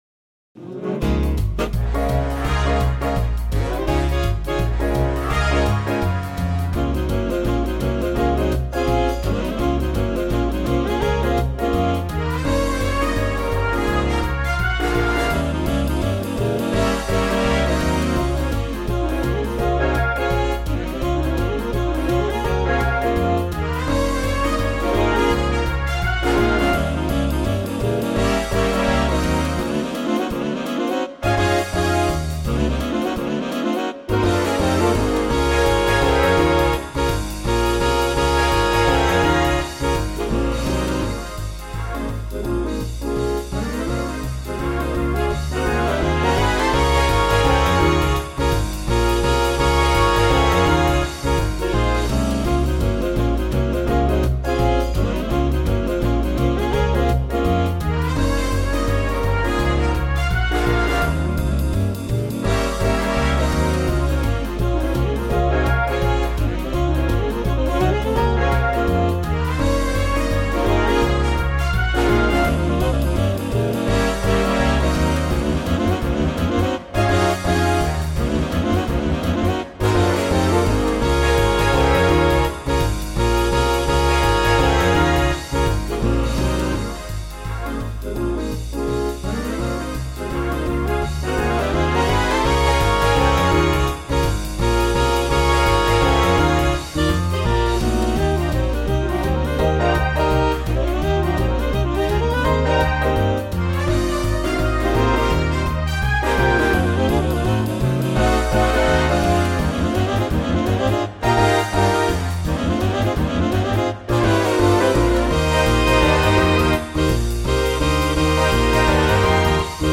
Sylva Train Runnin' | Big Band Swing